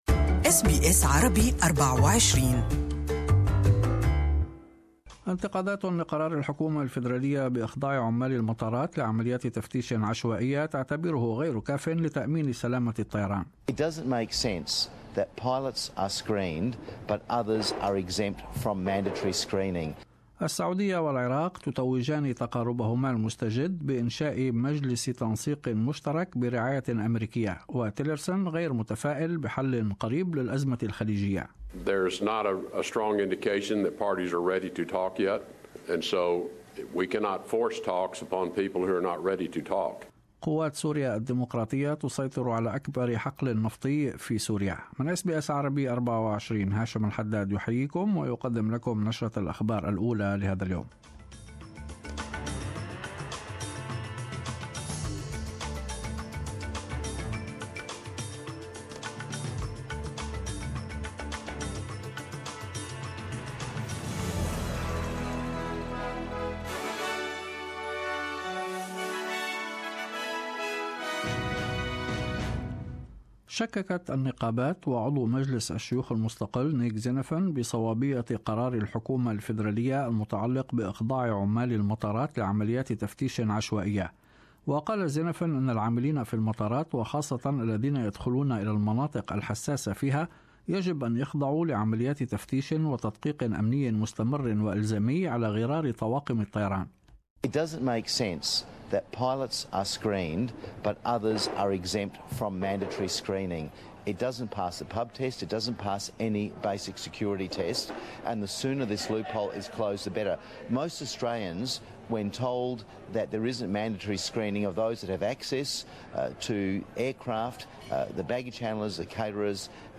In this bulletin ... ** Japanese Prime Minister Shinzo Abe (AH-bay) heading for a big election victory ** The World Health Organisation rescinds it's appopintment of Robert Mugabe (moo-GAH-bee) as a goodwill ambassador and ** In Rugby Union, Wallabies star Israel Folau (fol-OW) set to take a break from the game